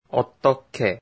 発音が難しい어떻게、音声で分かりやすく解説します。
어떻게 [オットケ]
日本人が苦手とする濃音떠にㅎパッチムとㄱがぶつかり、激音化が発生するので最終的な発音は[어떠케]になります。